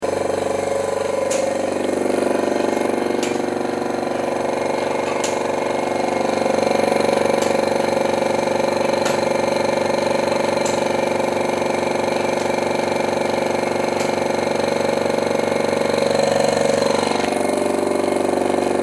充气配音.mp3